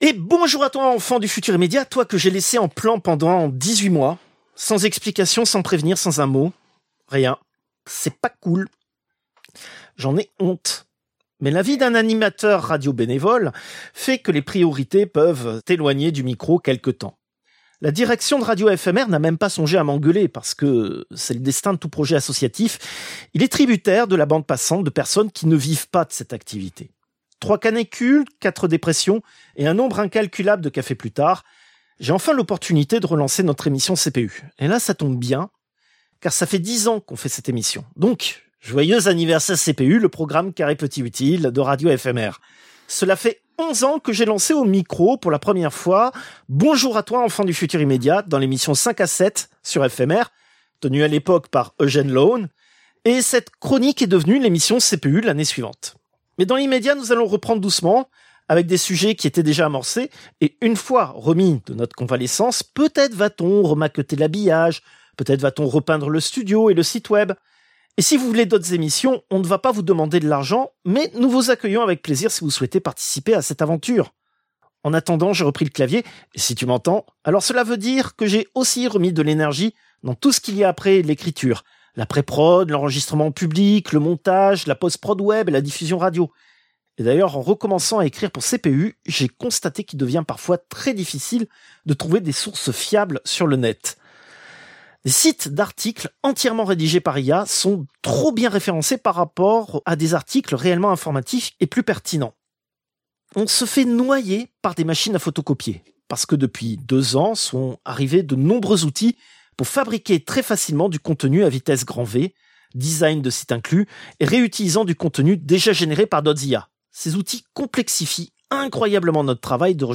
Extrait de l'émission CPU release Ex0221 : lost + found (rentrée 2025) première partie).